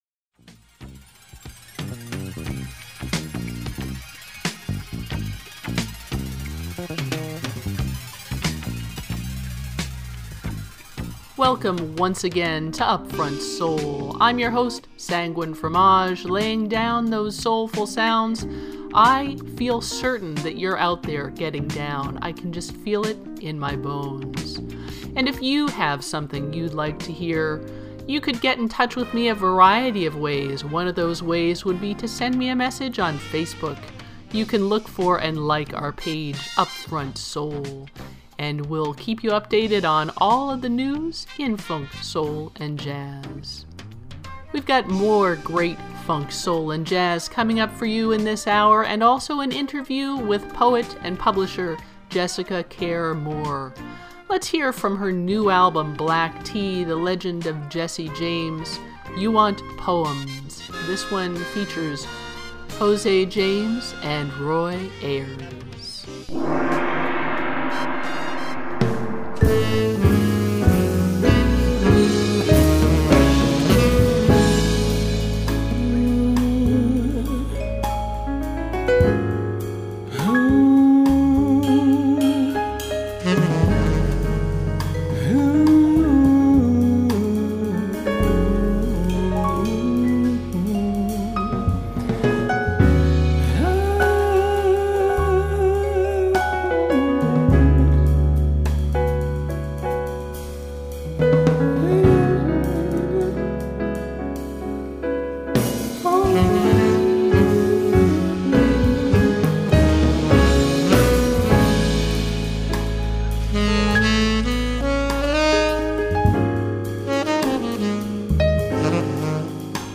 Funk, soul, and jazz
120 minutes of soulful sounds to which you may get down.